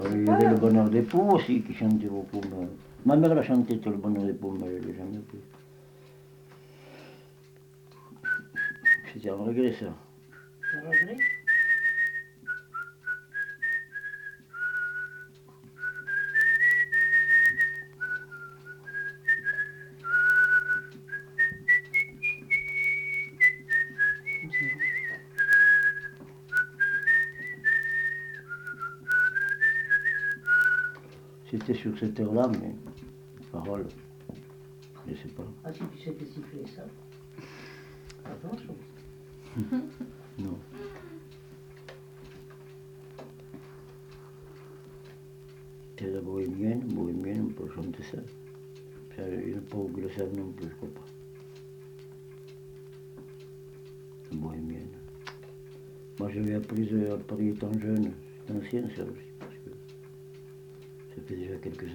Aire culturelle : Viadène
Département : Aveyron
Genre : chant
Effectif : 1
Type de voix : voix d'homme
Production du son : sifflé